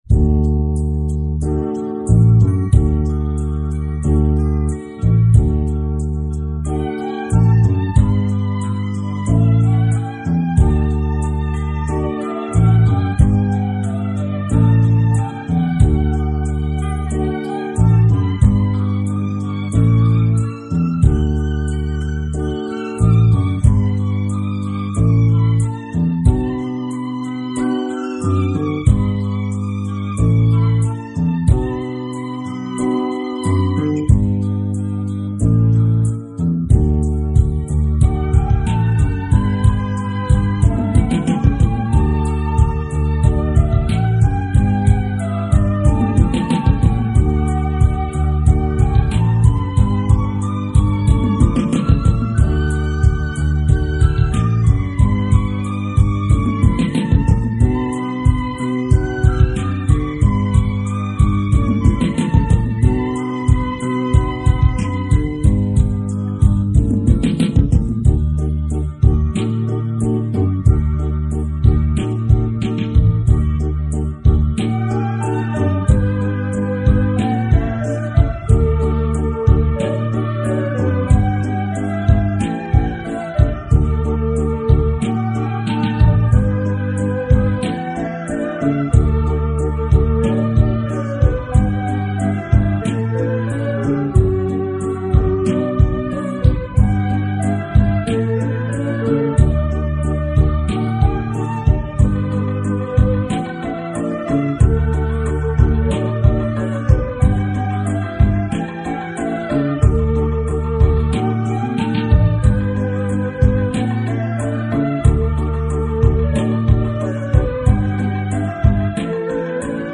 Teclados, guitarra acústica de 12 cuerdas y programaciones.